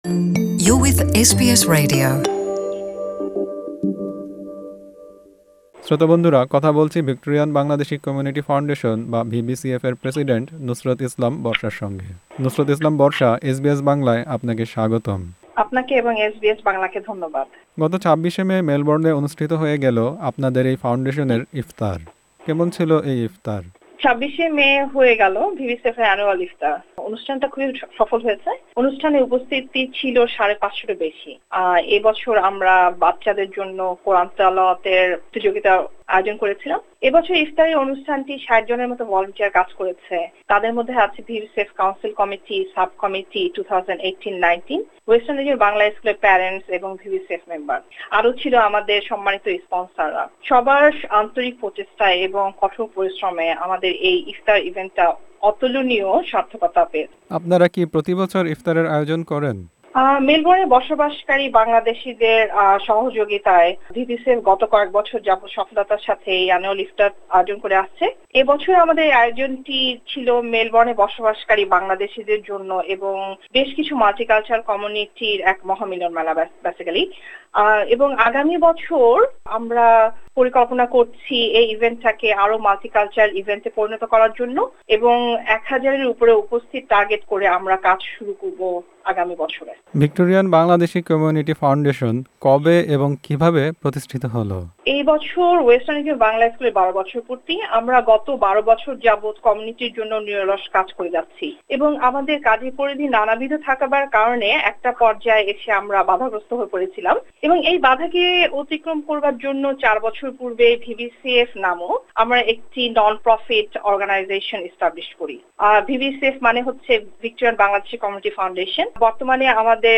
সাক্ষাত্কারটি